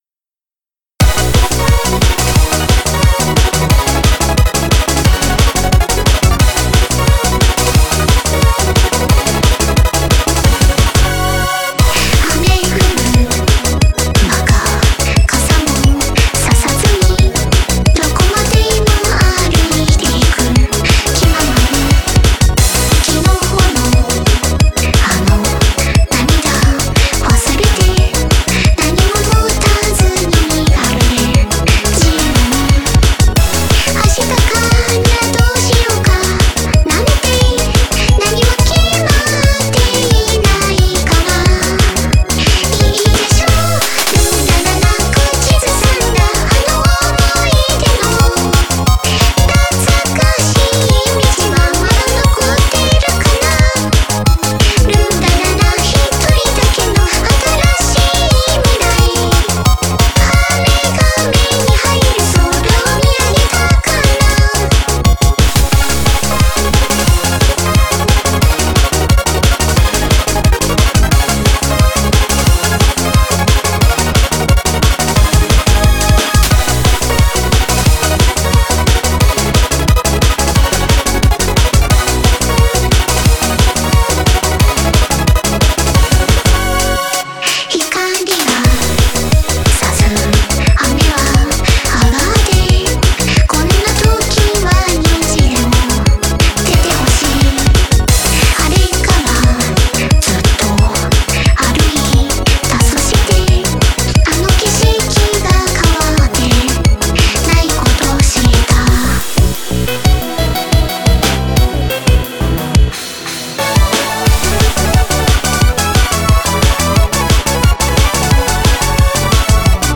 MP3 com vocal: